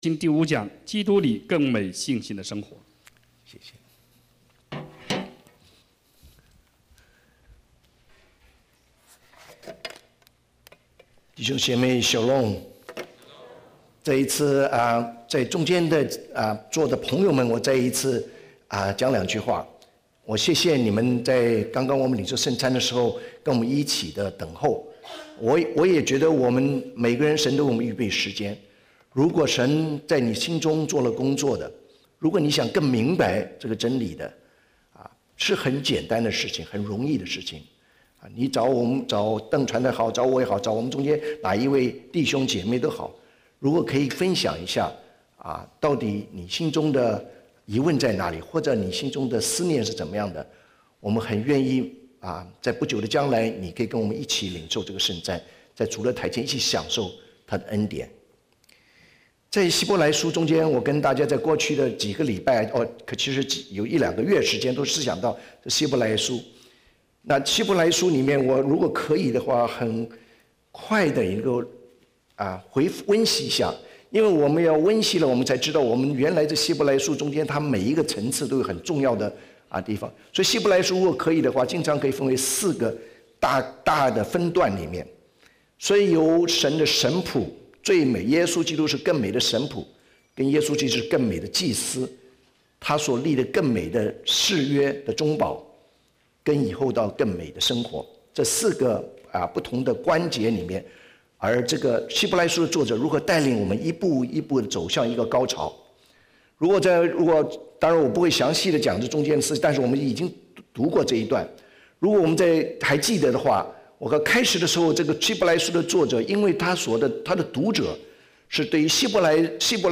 SERMONS | 講道 | Westwood Alliance Church
Guest Speaker